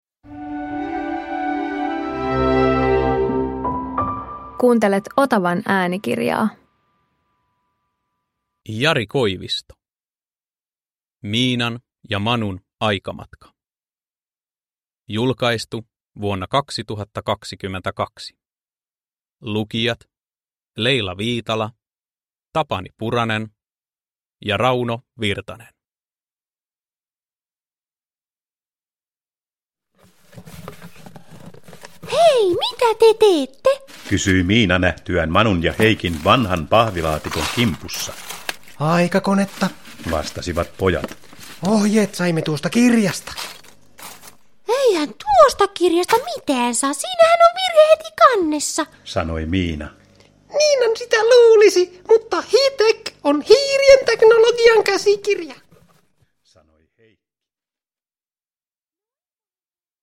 Miinan ja Manun aikamatka – Ljudbok – Laddas ner